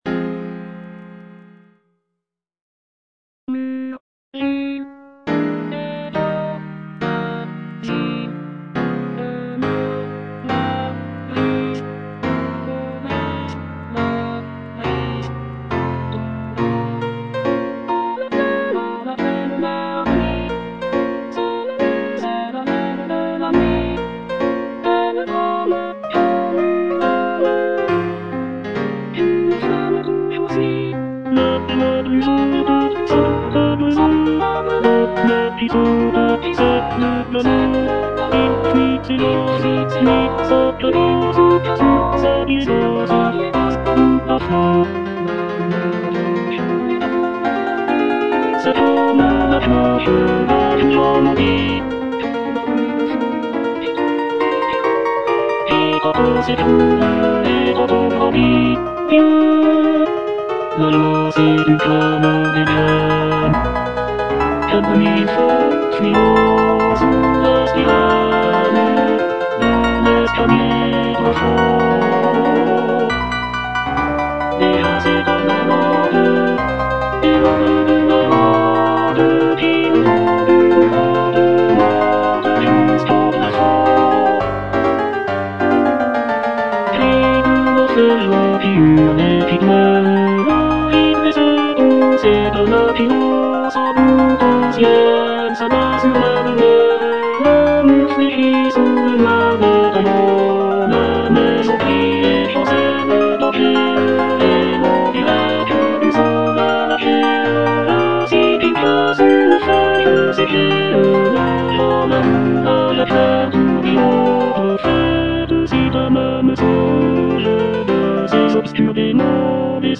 Inspired by a French poem of the same name by Victor Hugo, the piece evokes the mystical and haunting presence of the djinns, supernatural beings from Arab folklore. Fauré masterfully captures the eerie and otherworldly atmosphere through rich orchestration and dramatic shifts in dynamics. The music builds in intensity, creating a sense of unease and mystery before reaching a powerful climax.